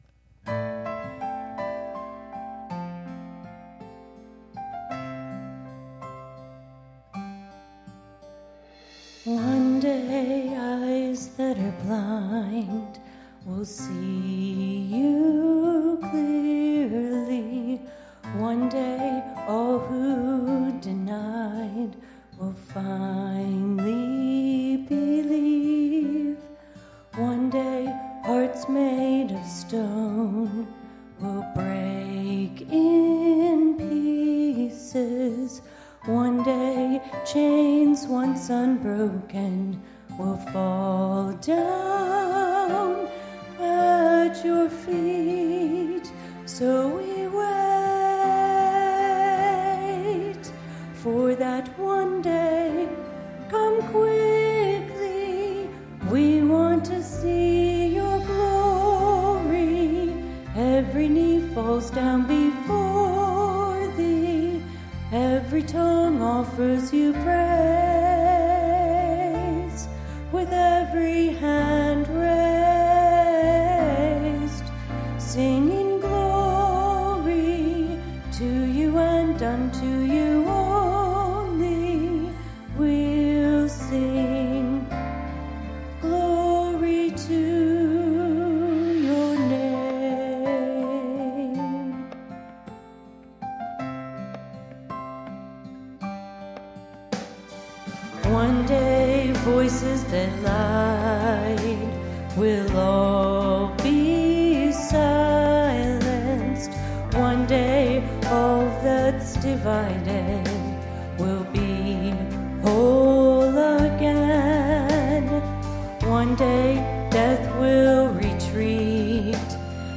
Sunday Morning Music
Solo